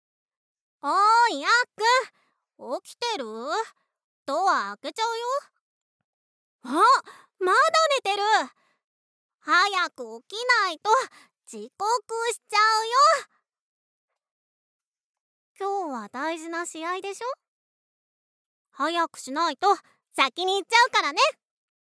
ボイスサンプル
幼なじみヒロイン